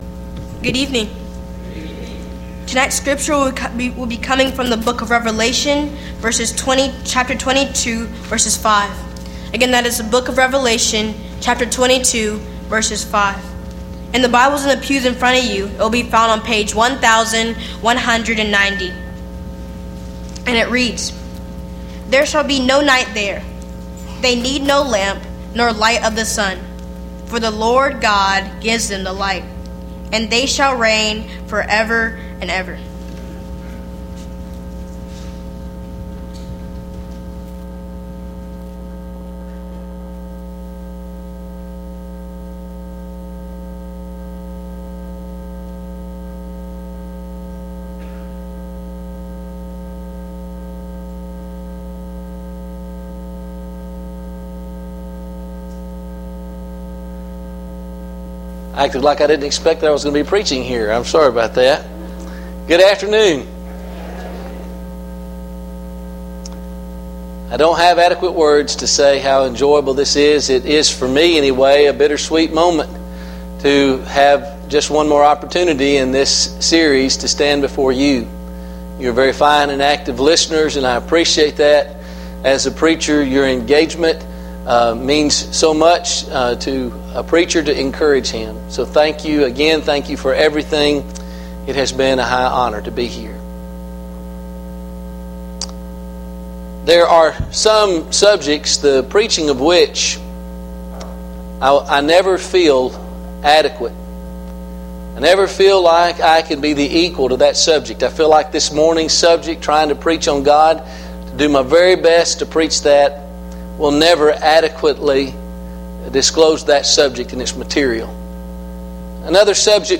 PM Worship